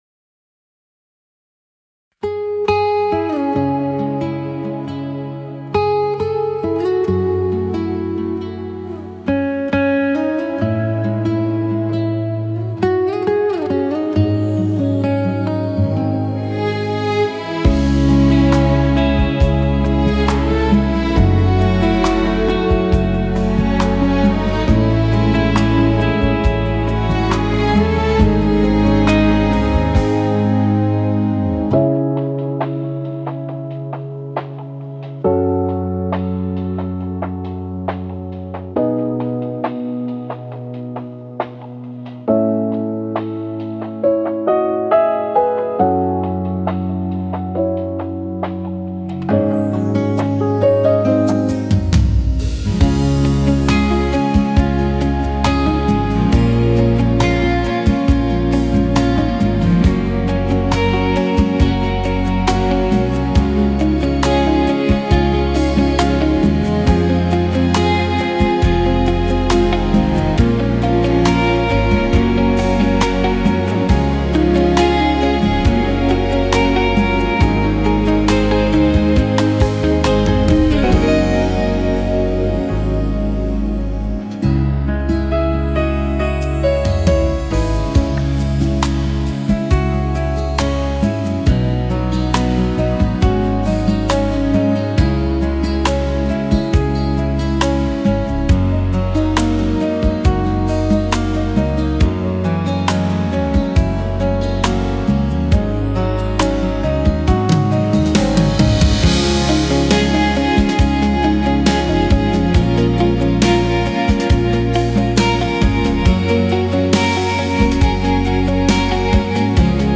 Thể Loại Đạo Ca: Đạo ca Cao Đài
Karaoke: